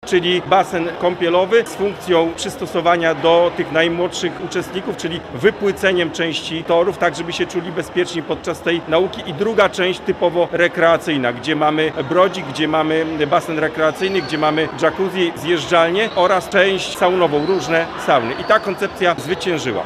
Stary basen „Delfinek” zostanie wyburzony, a w jego miejscu powstanie nowoczesny obiekt. Informacje przekazały władze miasta podczas Mistrzostw Łukowa w pływaniu.